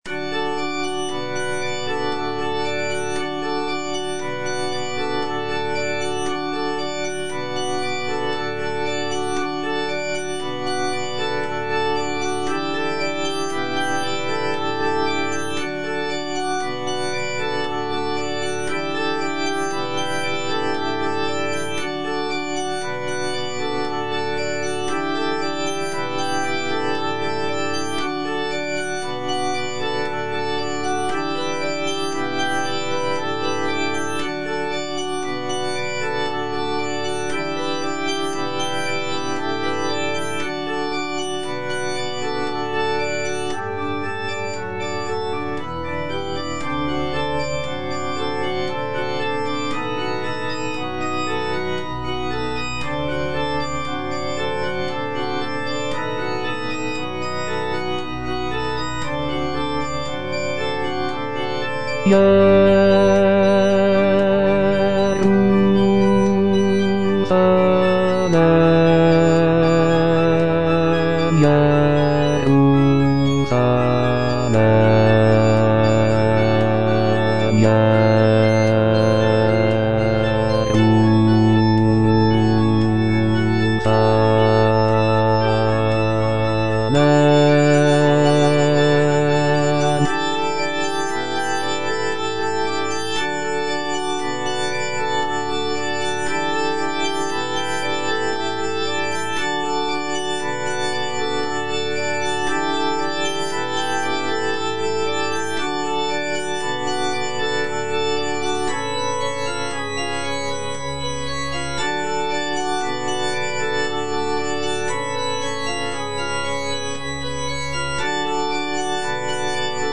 G. FAURÉ - REQUIEM OP.48 (VERSION WITH A SMALLER ORCHESTRA) In paradisum (bass II) (Voice with metronome) Ads stop: